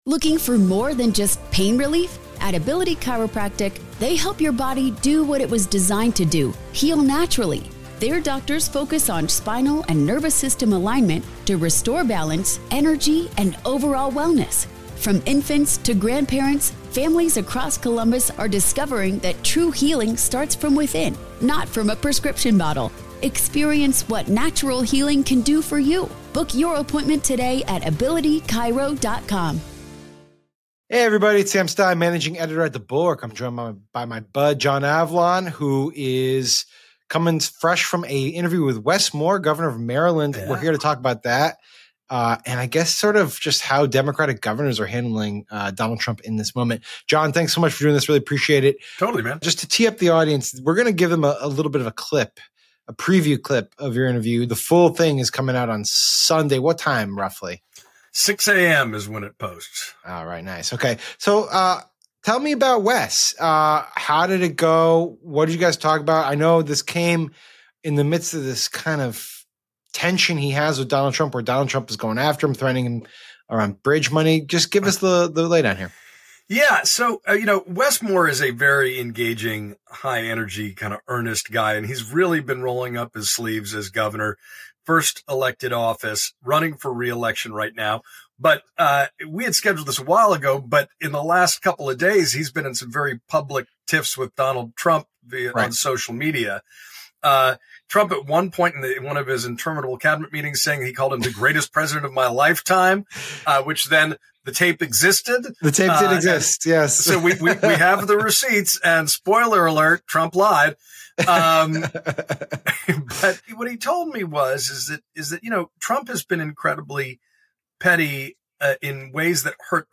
In an interview with The Bulwark—parts of which we are teasing now—Moore delivered some of his sharpest pushback against Trump to date, accusing him of being motivated by pettiness and partisanship. And he outlined how Maryland has responded creatively to the challenges Trump has posed.